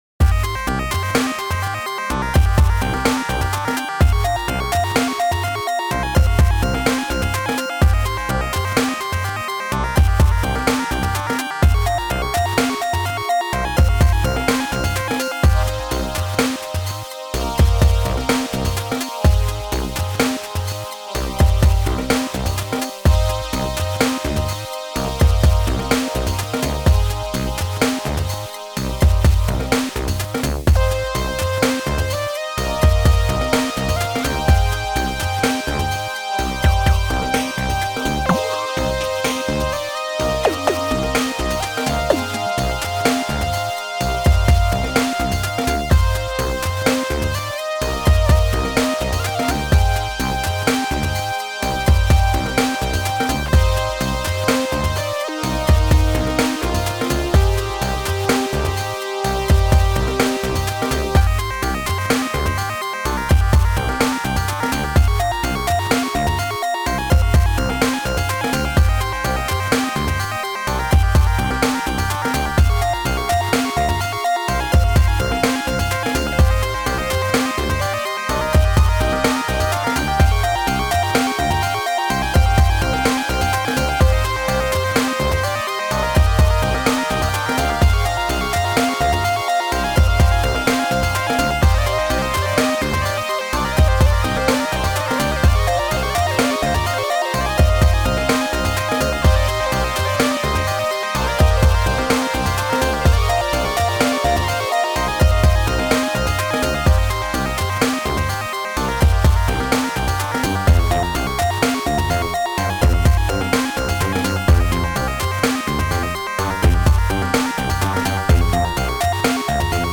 Genre: Arcade | Old School | Retro Gaming